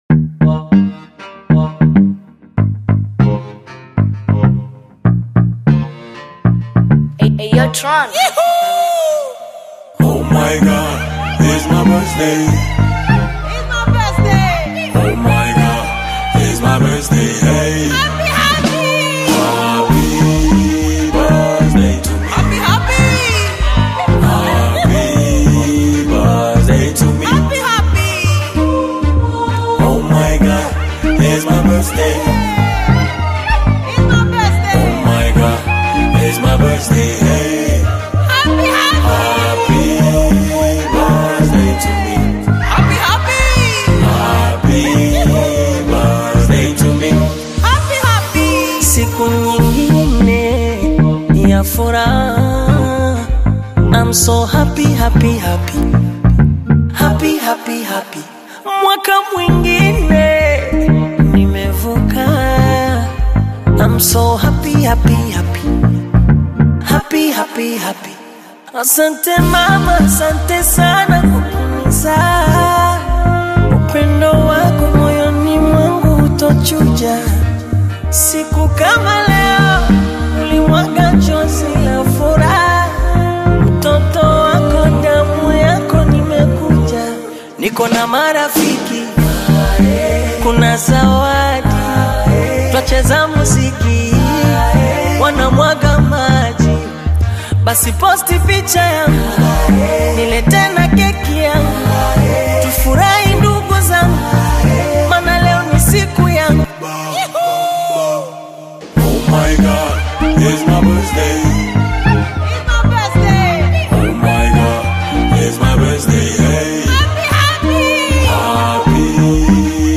شاد عاشقانه